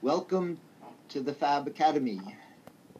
So I compressed some audio (Neil's voice of course) that I clipped from previous weeks of fab academy, you can download the original .m4a files down here: